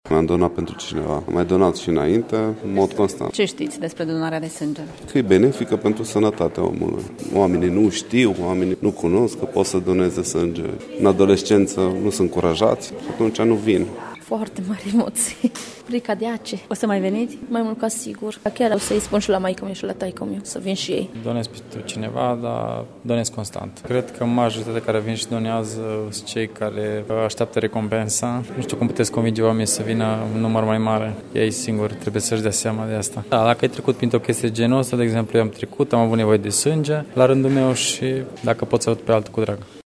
Lipsa donatorilor de sânge se explică prin lipsa educației în acest sens la adolescenți dar și lipsa de empatie a populației, spun donatorii mureșeni: